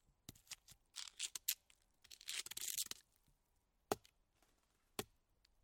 Scraping timber
Duration - 5 s Environment - Wooded, Open air, little breeze, sheep. Description - Rubbing, hitting, scraping wood. Impacting objects against one another.